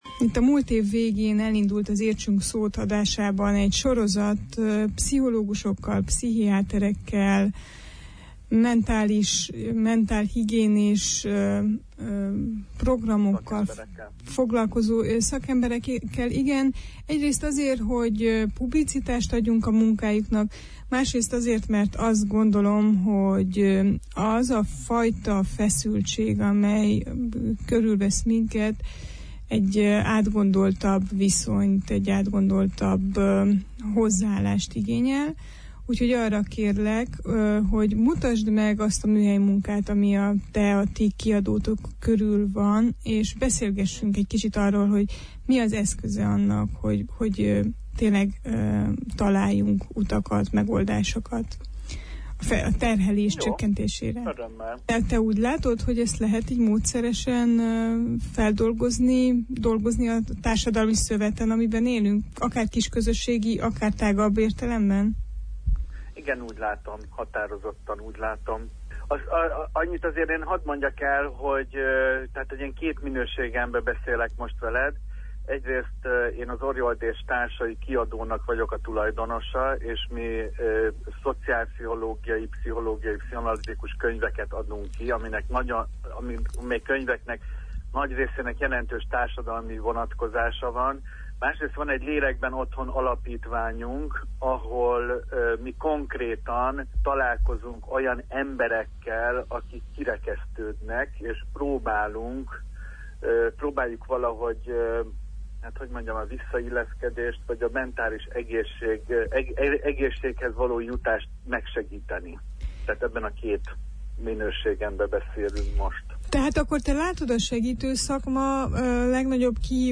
A pozitív példákról, a magyarul is elérhető szakkiadványokról továbbképzésekről és az áldozatvédelemről szóló beszélgetésünk hanganyaga itt hallgatható vissza.